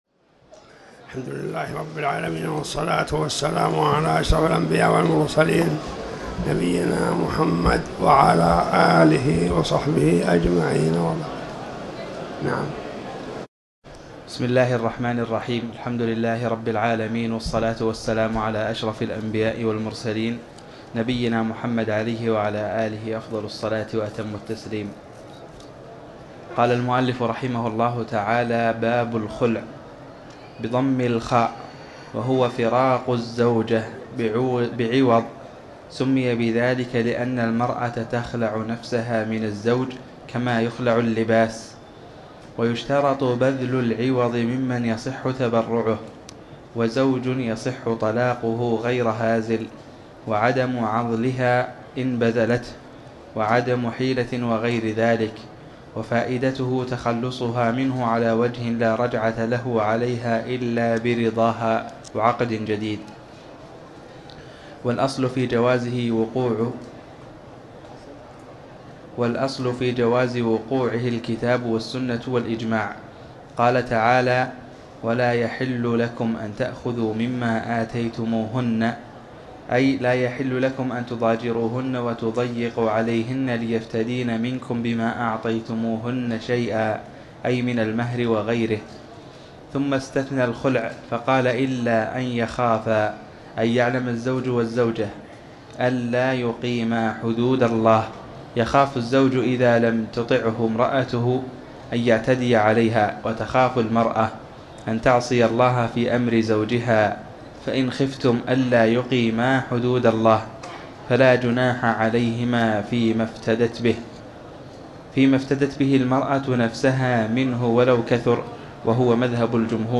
تاريخ النشر ١٨ ذو القعدة ١٤٤٠ هـ المكان: المسجد الحرام الشيخ